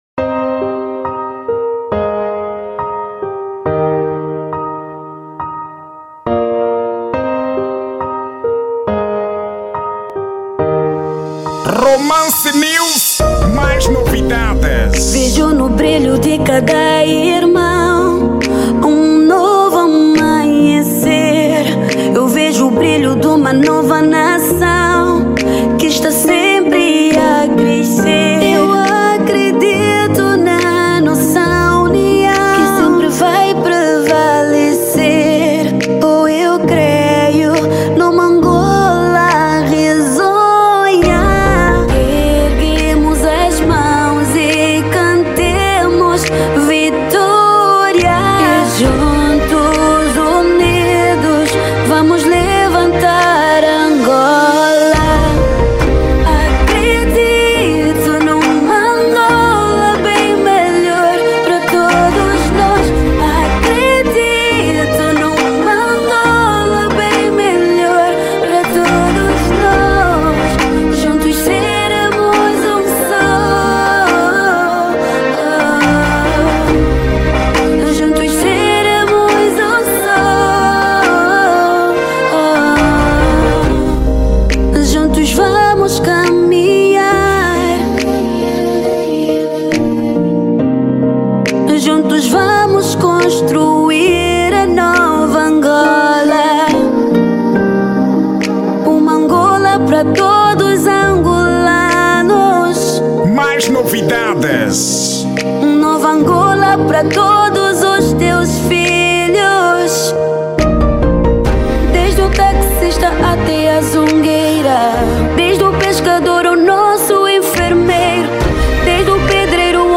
Estilo: zouk